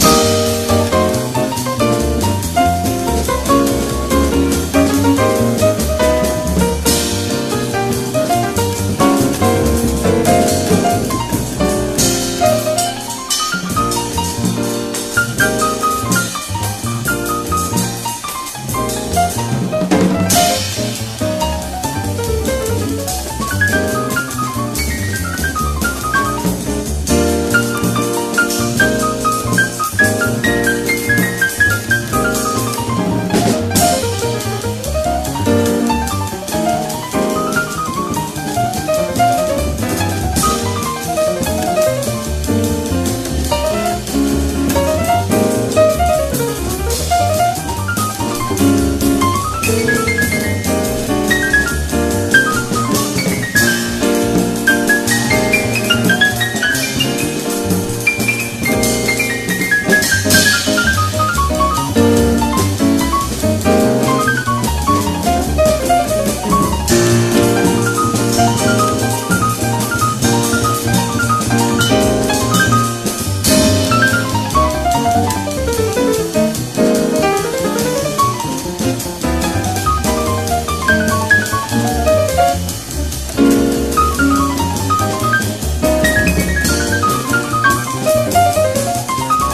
JAZZ / JAPANESE
しなやかで的確な指さ捌きから美しさが零れ落ちています。